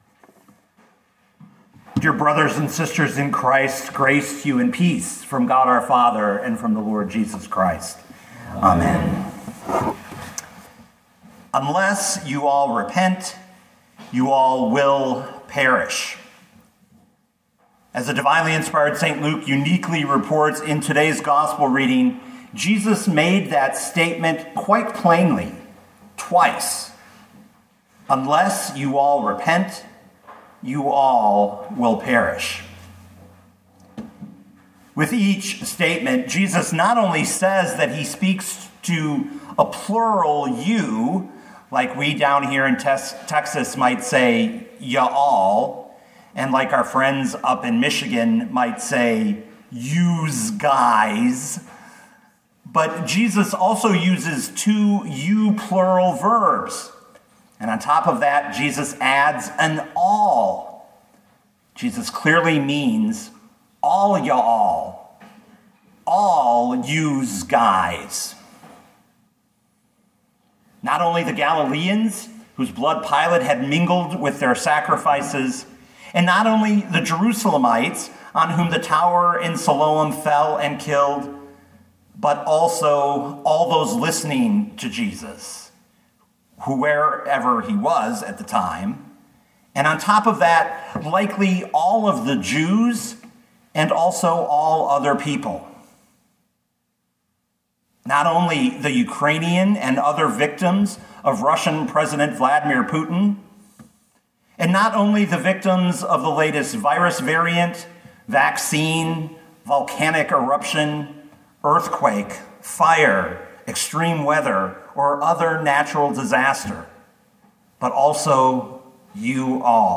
2022 Luke 13:1-9 Listen to the sermon with the player below